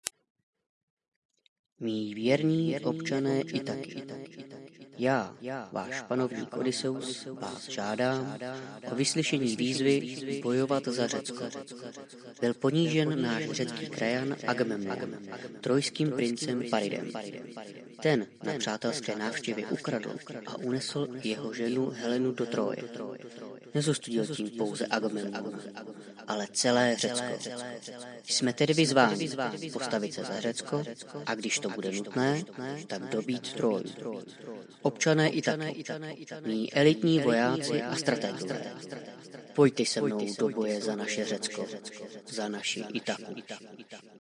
MOTIVAČNÍ NAHRÁVKA – doporučujeme přehrát!
Odysseův_proslov.mp3